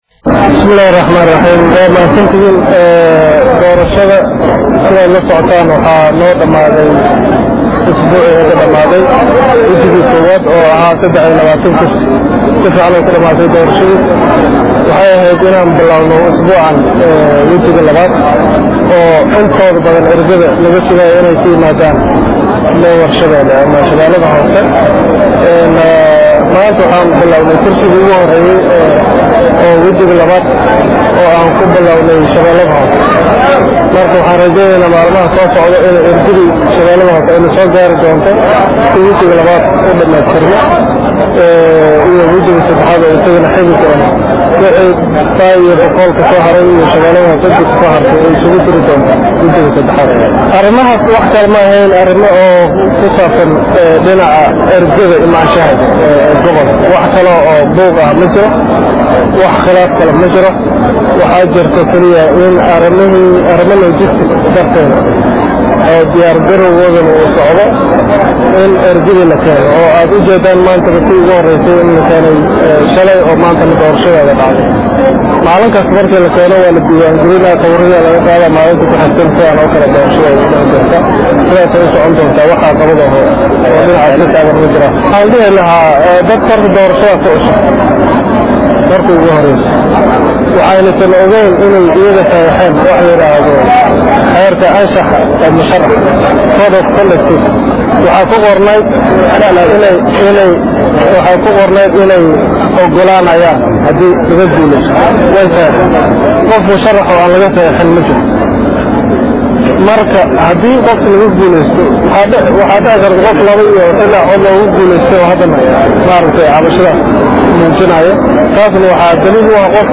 Baydhabo(INO)- Maxamed C/laahi Mursal, gudoomiyaha Gudiga doorashooyinka Koonfur Galbeed Soomaliya ayaa ka hadley Hakadka ku yimid Doorashda Wajaga 2aad Ee Aqalka Hoose iyo Sidoo kale Cabashooyinka Ka Soo yeeraya Musharaxiintii la reebey.